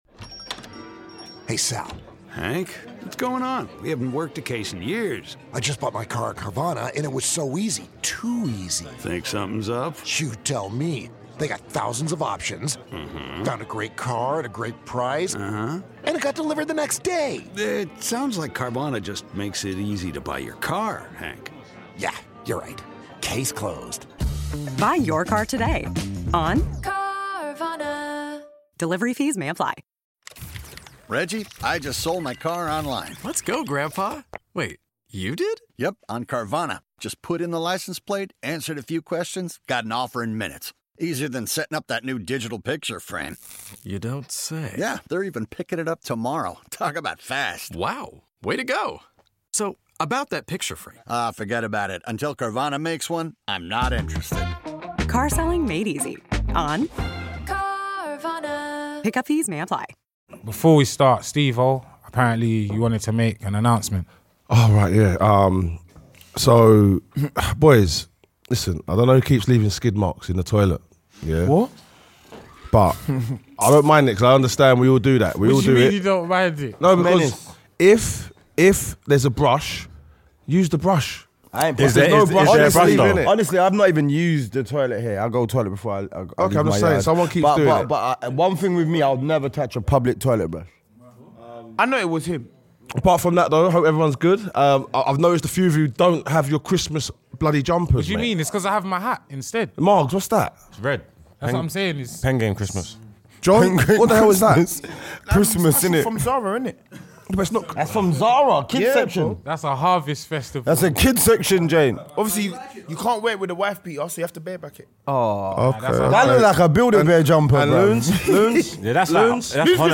A 12-MAN PANEL DEBATES, ARGUES, AND GOES IN ON THE CURSE OF FILTHY AND HOW IT’S HIT HARD THIS SEASON! ARE ASTON VILLA GENUINE TITLE CONTENDERS, OR ARE THEY JUST PRETENDERS?! WHAT DO THE LEAGUE LEADERS, ARSENAL, AND THEIR CLOSEST RIVALS NEED TO DO TO BE CROWNED CHAMPIONS THIS SEASON?